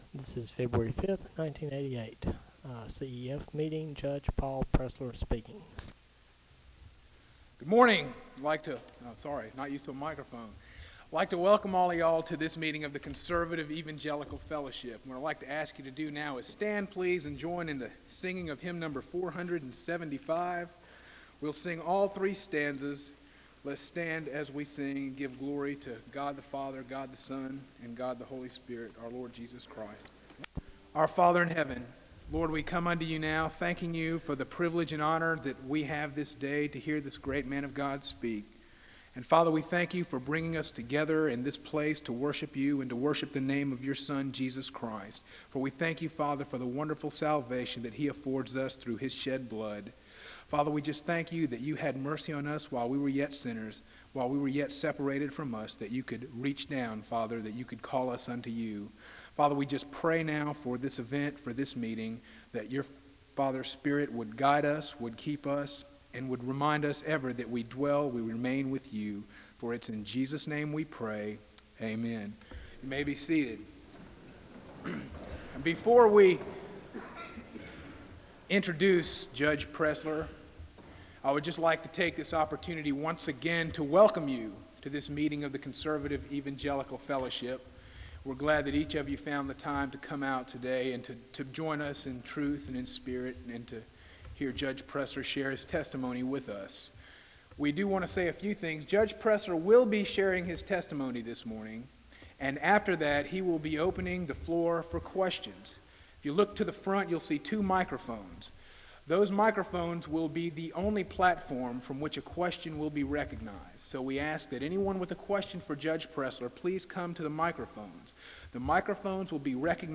SEBTS_Chapel_Paul_Pressler_1988-02-05.wav